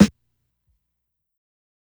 SNARE_GROWTH.wav